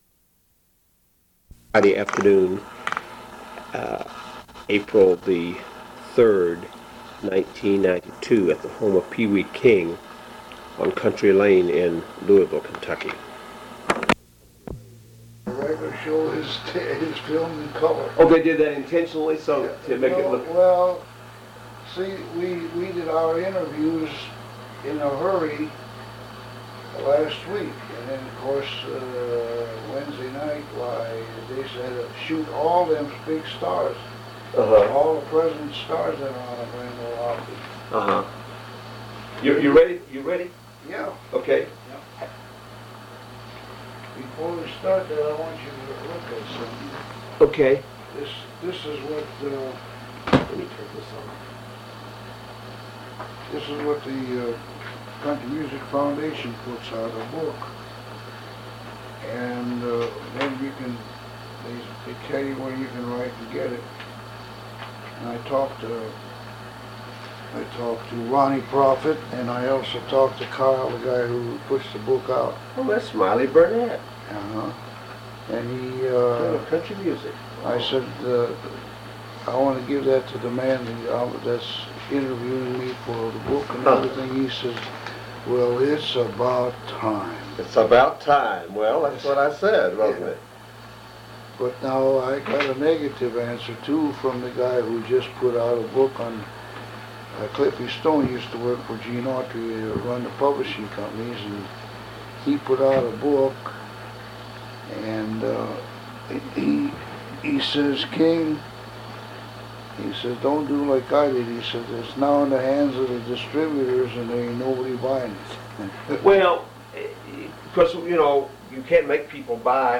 Oral History Interview with Pee Wee King